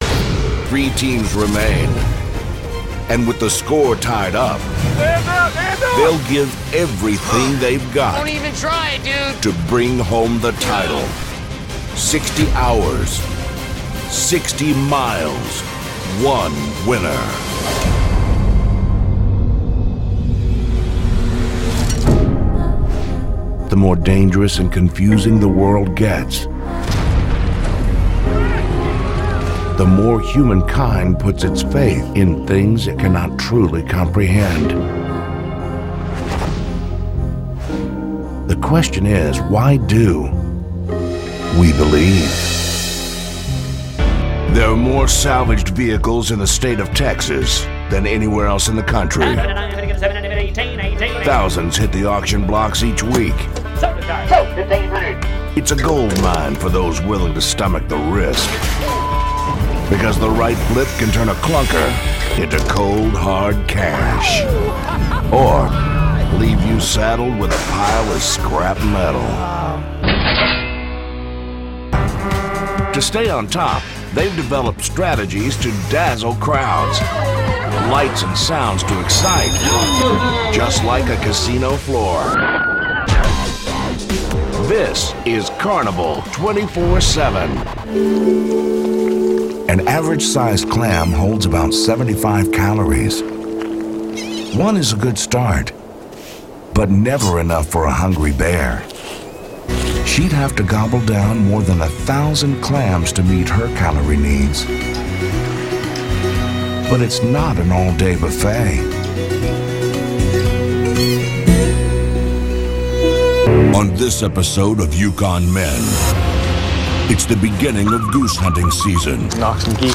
chicago : voiceover : commercial : men